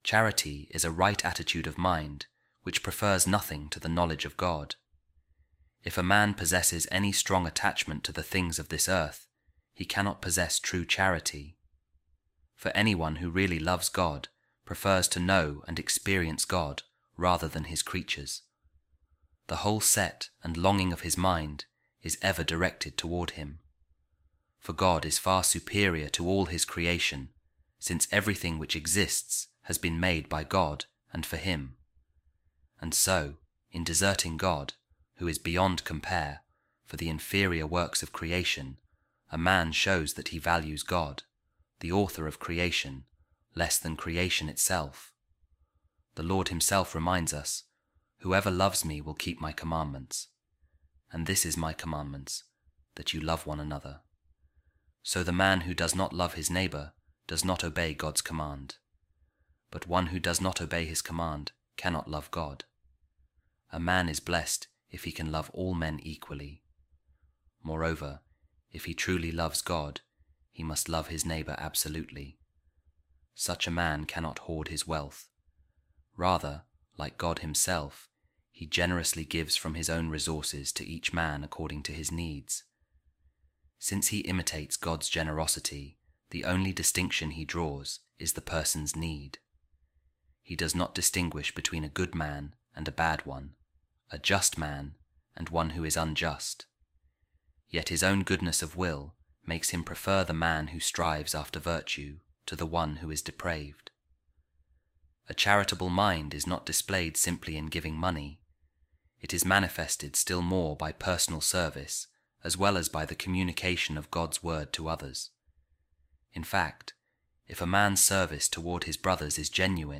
A Reading From The Four Centuries On Charity By Saint Maximus The Confessor | Without Charity All Is Vanity Of Vanities